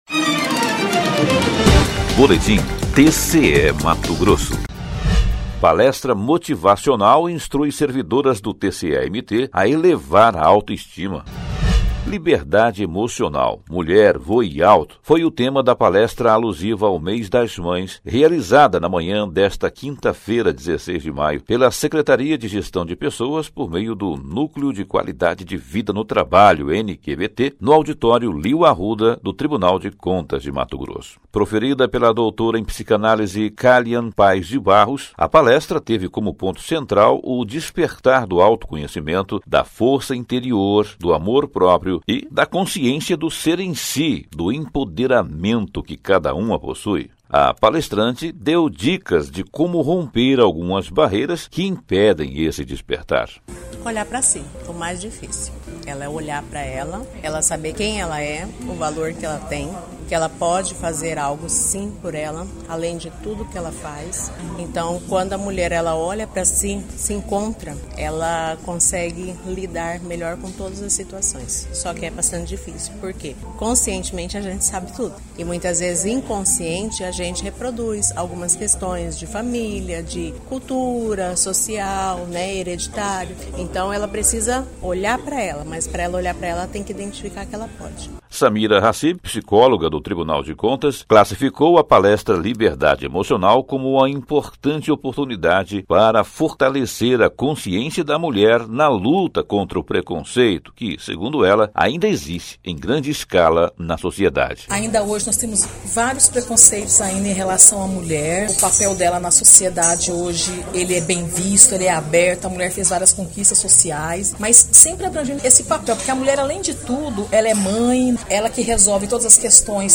17 - Palestra motivacional instrui servidoras do TCE-MT a elevar auto-estima.mp3 (5.93 MB)